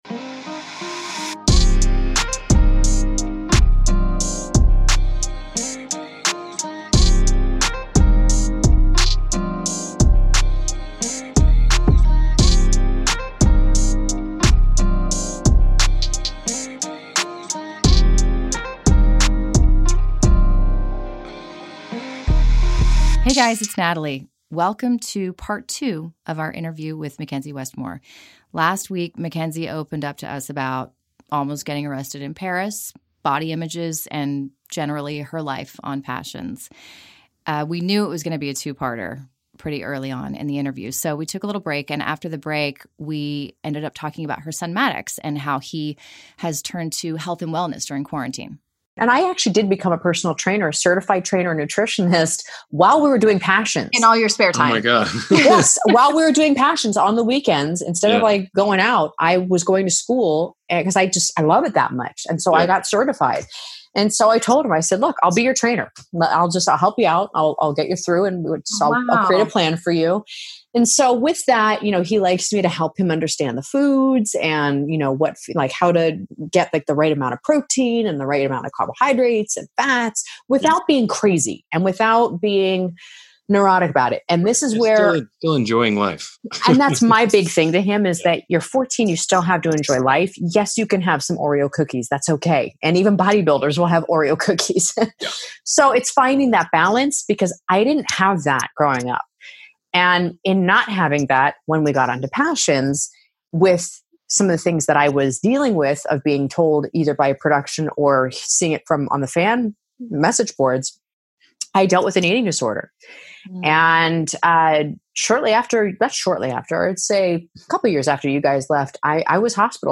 Part Two of Our Exclusive Interview With McKenzie Westmore aka “I Survived Passions!” (Ep 7)